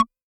Index of /musicradar/retro-drum-machine-samples/Drums Hits/Tape Path B
RDM_TapeB_SY1-Perc03.wav